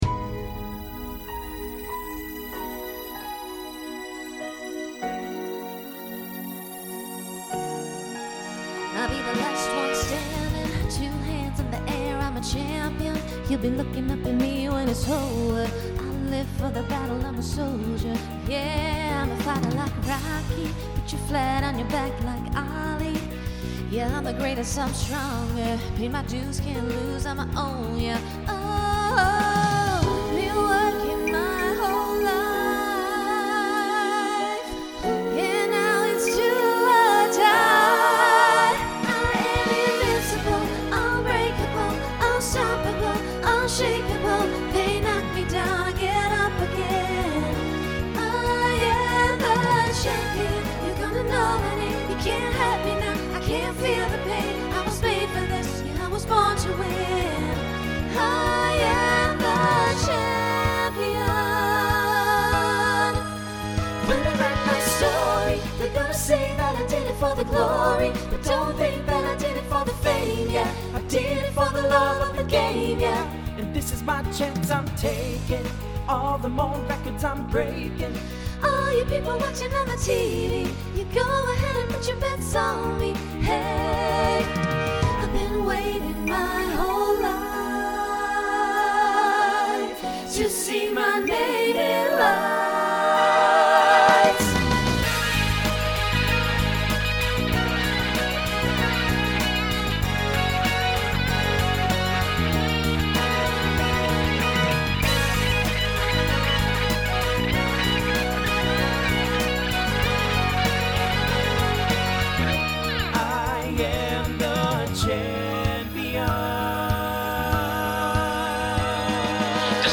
Voicing SATB Instrumental combo Genre Country , Rock